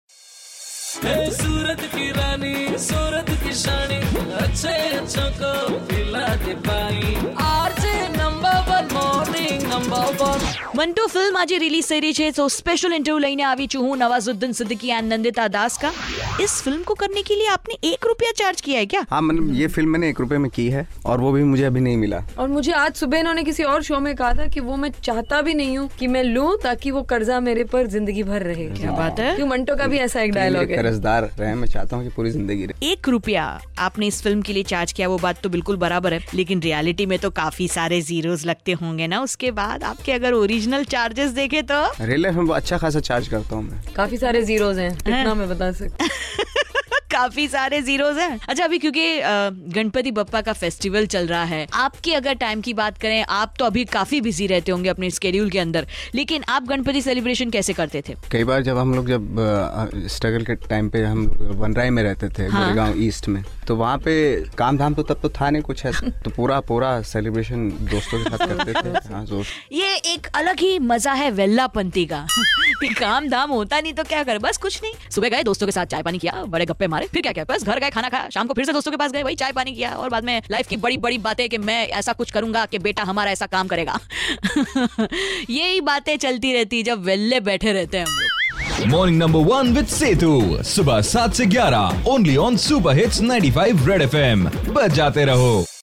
IN CONVERSATION WITH NANDITA DAS AND NAWAZ